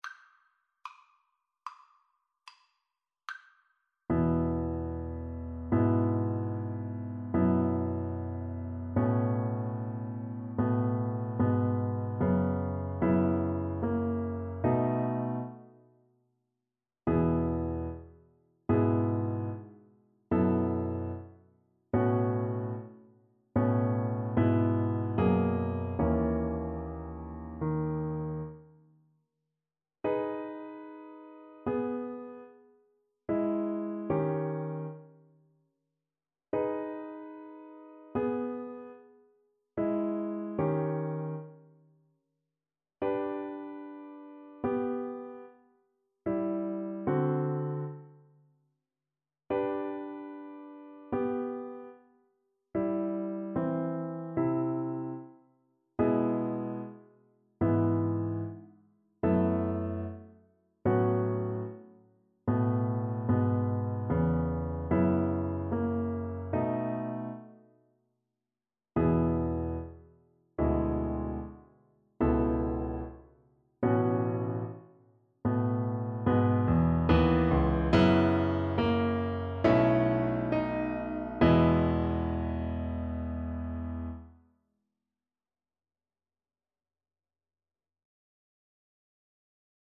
E major (Sounding Pitch) (View more E major Music for Violin )
4/4 (View more 4/4 Music)
Andante con moto =74 (View more music marked Andante con moto)
Classical (View more Classical Violin Music)